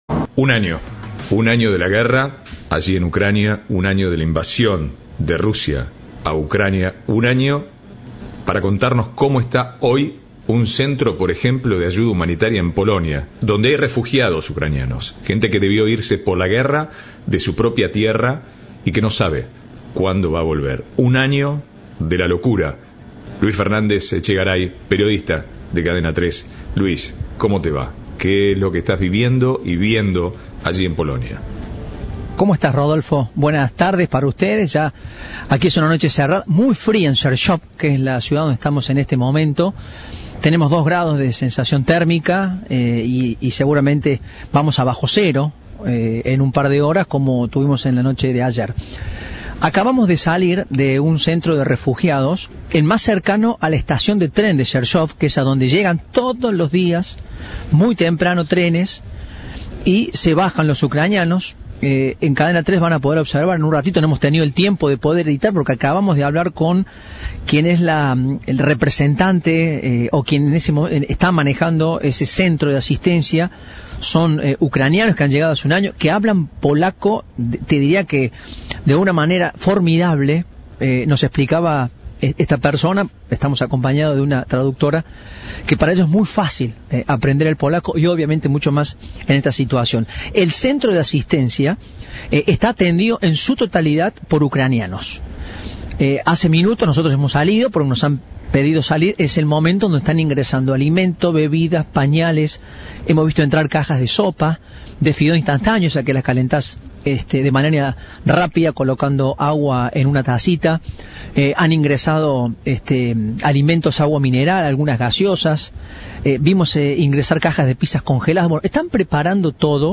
recorrió un centro de refugiados ucranianos en Polonia, quienes hace un año debieron abandonar su país por el conflicto con Rusia.
habló con la encargada de uno de esos centros quien le relató la crudeza de la situación en la que viven muchos de los ucranianos refugiados.